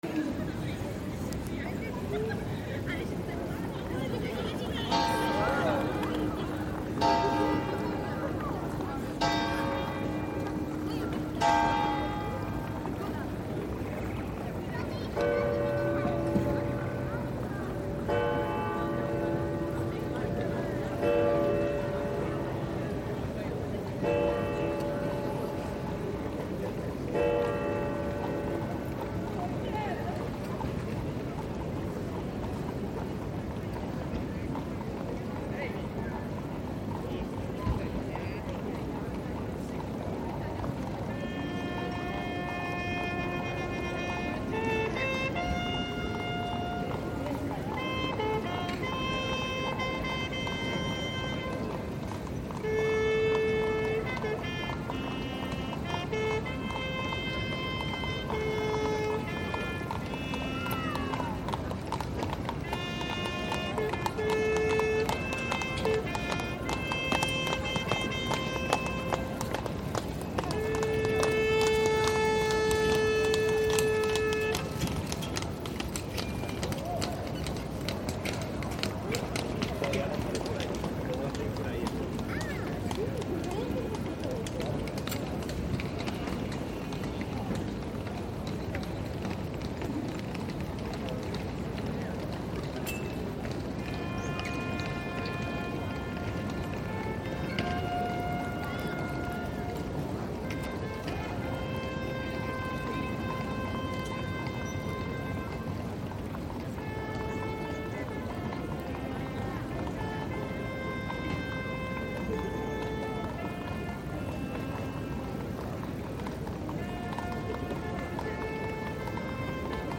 Probably Poland's most iconic sound, the hejnał mariacki (literally "Saint Mary's dawn") is a trumpet call that sounds every hour on the hour from the highest tower of St Mary's Church in Kraków's rynek glówny (main square).
The bugler plays the same call four times, once in each of the cardinal directions. This tradition dates back to medieval times, when the call was used to signal the opening and closing of the city gates at dawn and dusk.
The theme's abrupt end commemorates the Mongol-Tatar siege of 1241, when the trumpeter warning the city of the imminent threat was shot in the throat by an arrow mid-way through the call.